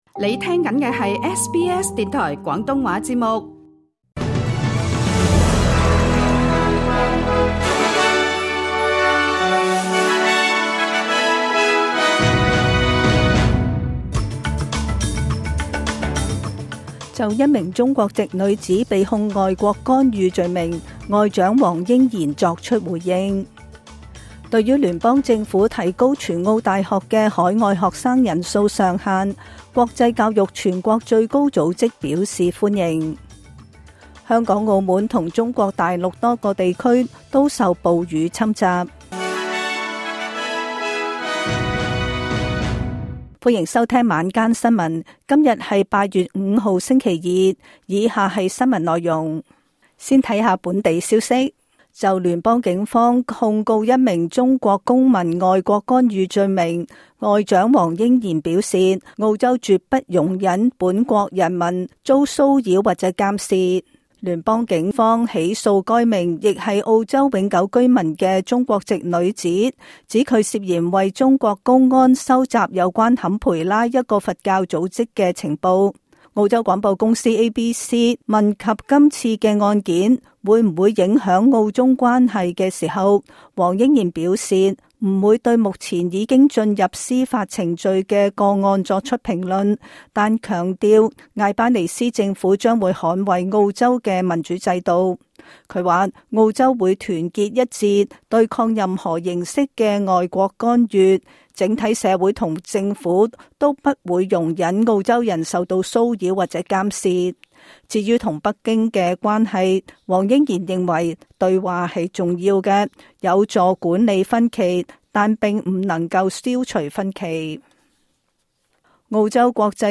SBS 晚間新聞（2025 年 8月 5日）